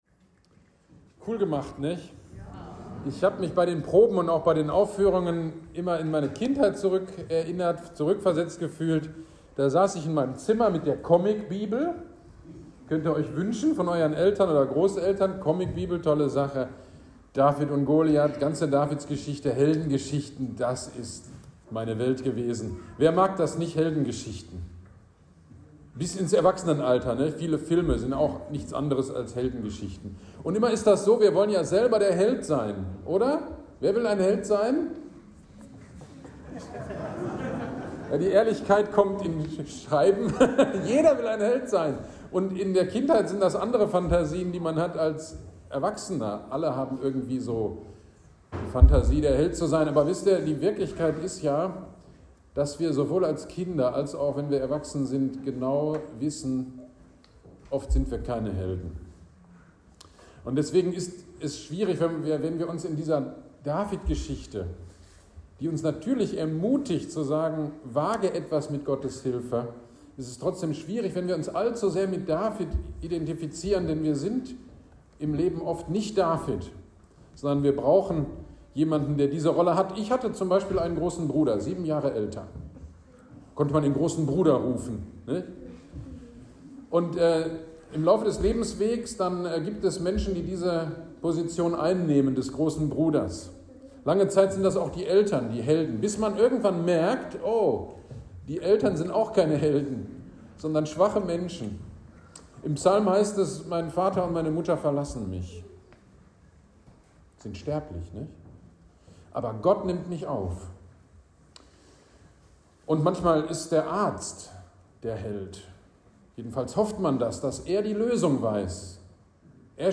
GD am 24.09.23 Predigt zum Kindermusical David und Goliath